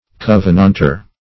Covenantor \Cov"e*nant*or`\ (-?r`), n. (Law)